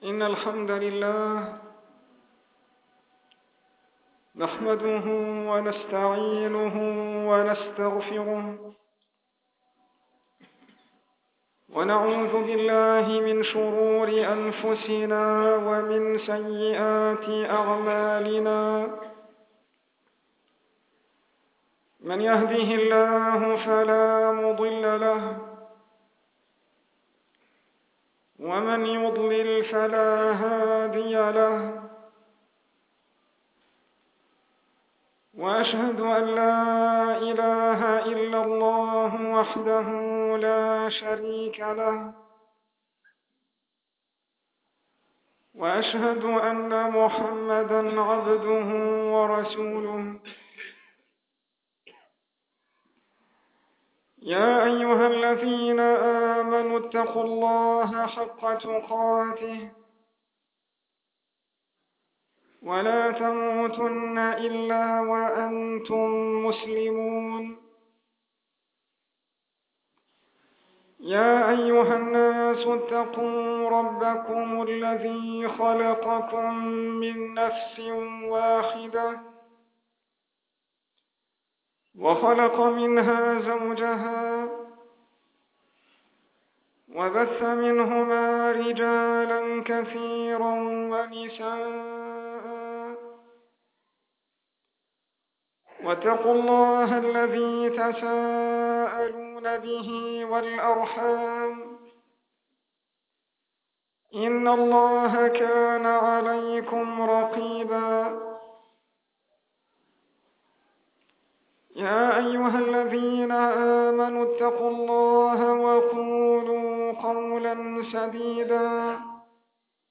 الخطبة (وفيها التحذير من بدعة التكفير، والرد على الذين يكفرون بموالاة الكفار من غير تفصيل)
خطبة الجمعة